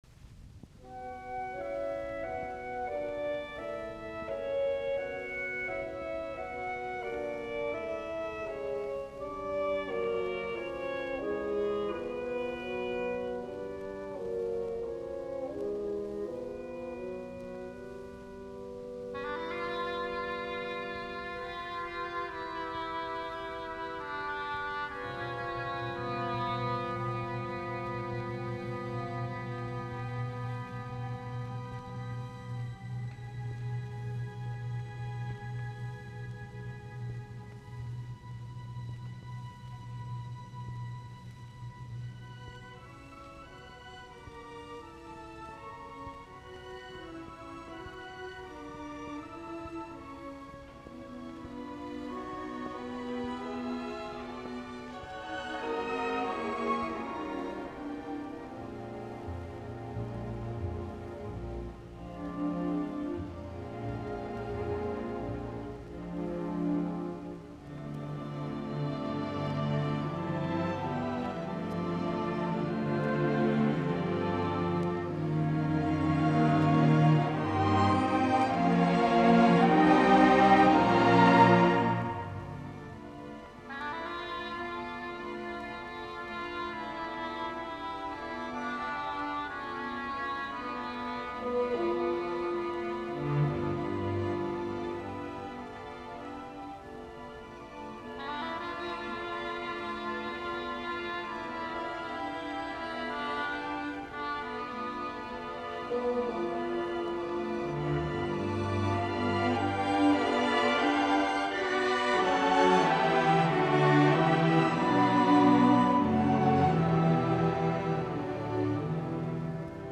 naiskuoro, ork.
Soitinnus: Ork.